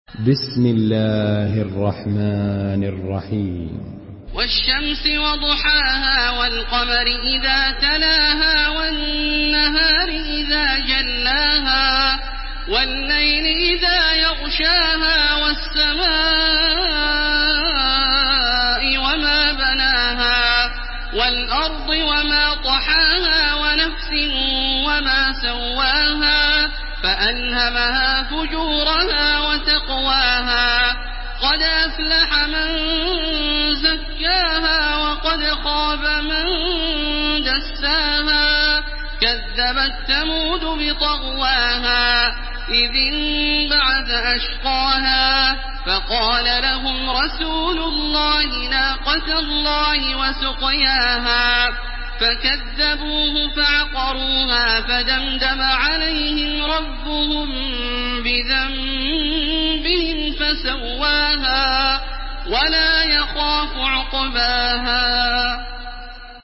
Surah Şems MP3 by Makkah Taraweeh 1430 in Hafs An Asim narration.
Murattal